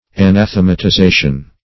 Anathematization \A*nath`e*ma*ti*za"tion\, n. [LL.